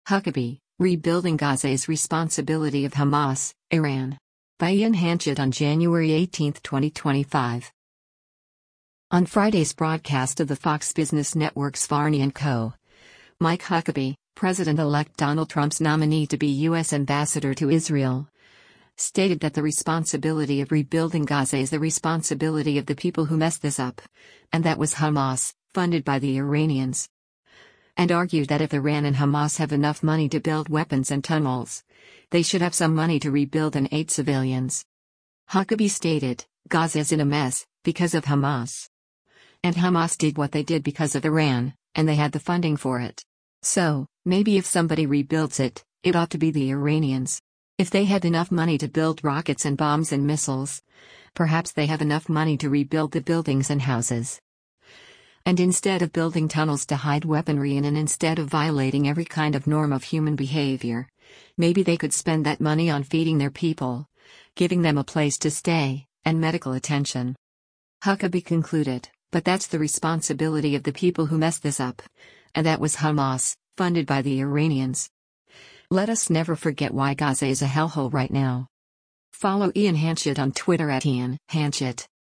On Friday’s broadcast of the Fox Business Network’s “Varney & Co.,” Mike Huckabee, President-Elect Donald Trump’s nominee to be U.S. Ambassador to Israel, stated that the responsibility of rebuilding Gaza is “the responsibility of the people who messed this up, and that was Hamas, funded by the Iranians.” And argued that if Iran and Hamas have enough money to build weapons and tunnels, they should have some money to rebuild and aid civilians.